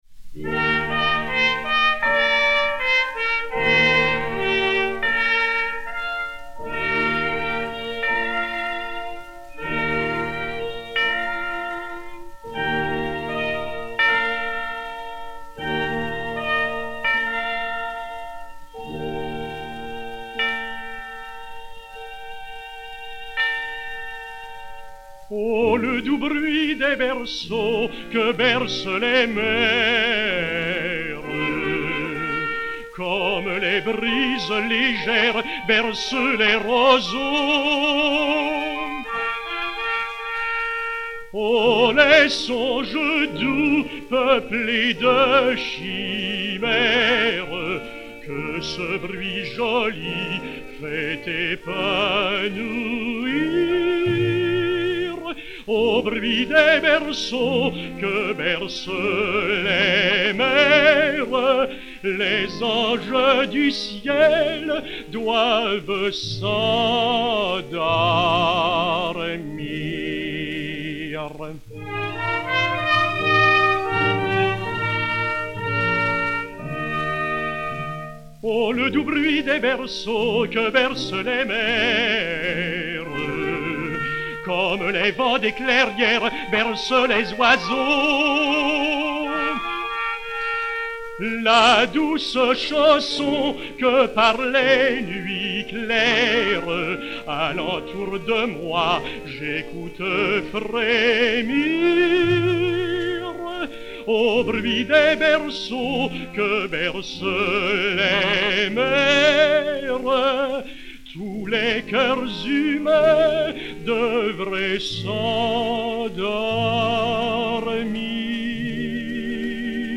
chanson (par.